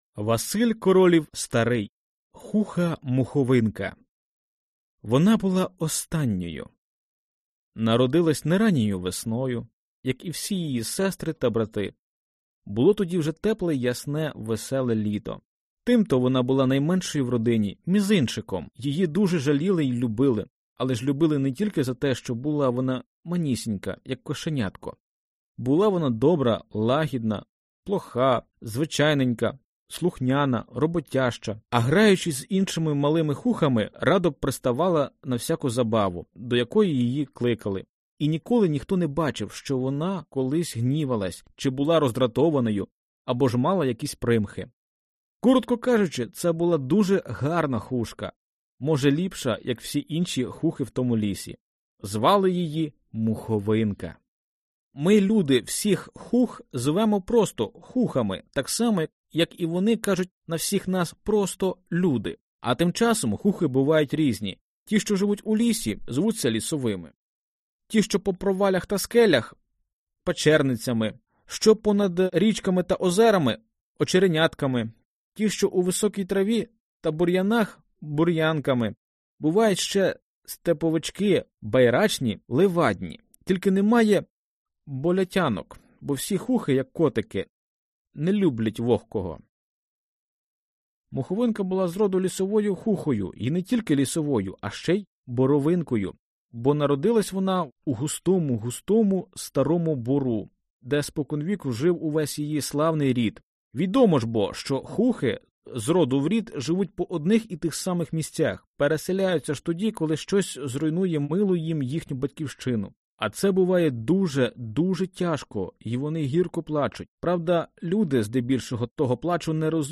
Аудиокнига Хуха-Моховинка | Библиотека аудиокниг
Прослушать и бесплатно скачать фрагмент аудиокниги